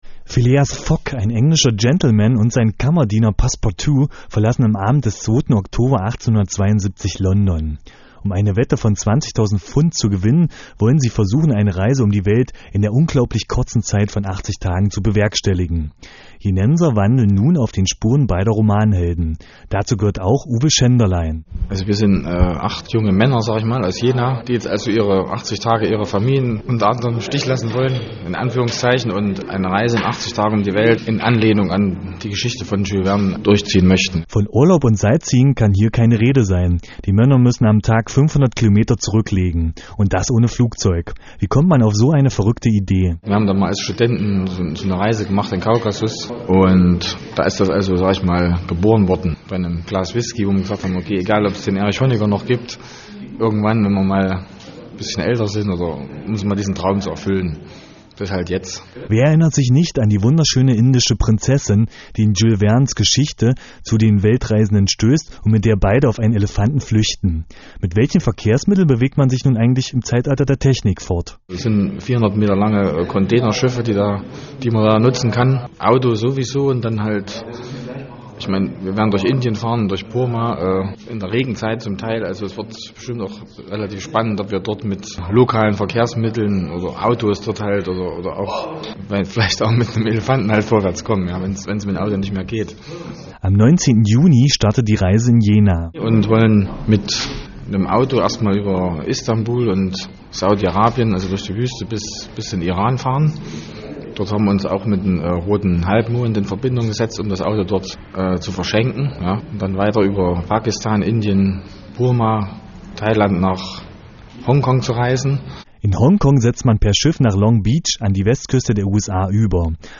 Interview im mp3 format